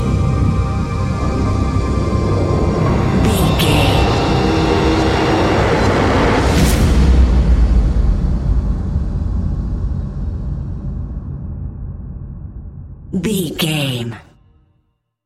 Fast paced
In-crescendo
Ionian/Major
C♯
industrial
dark ambient
EBM
drone
synths
Krautrock